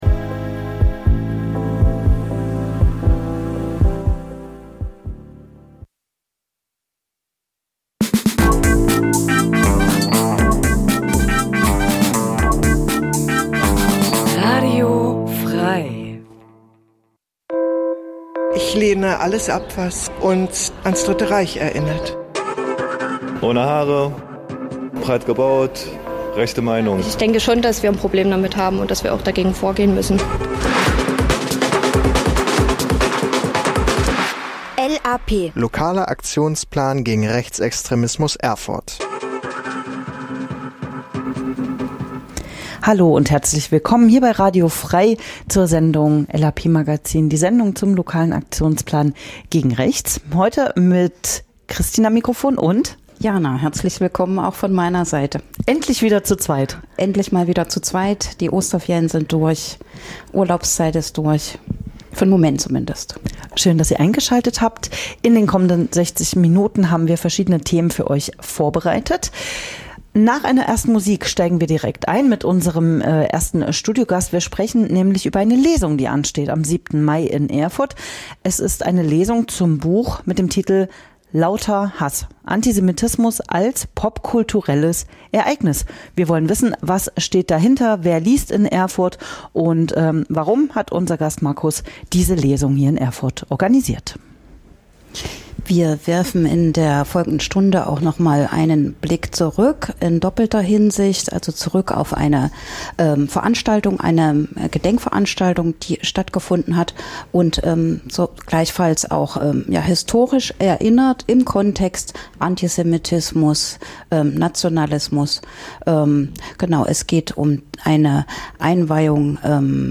Interviews zu aktuellen Themen
Musikrubrik "Coole Cover"